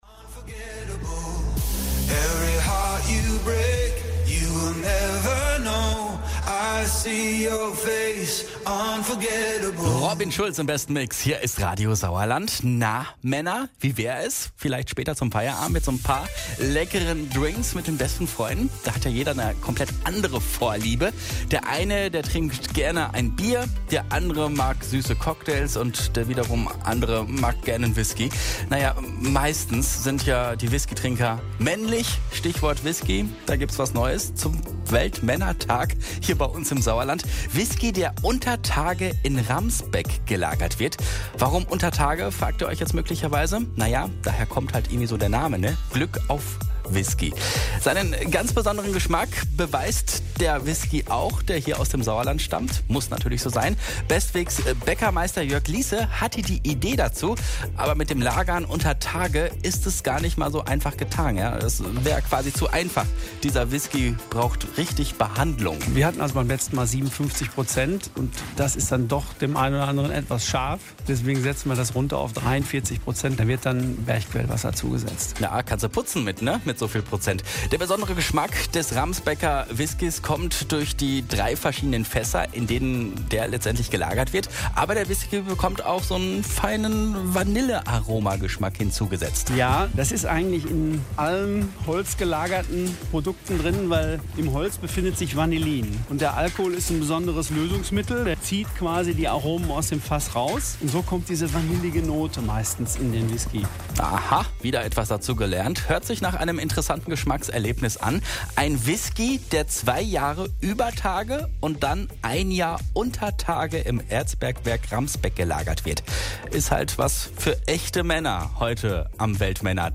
Radio Sauerland Beiträge vom 19.11.2019 zum Glück auf Whisky
mitschnitt-glueck_auf-whisky.mp3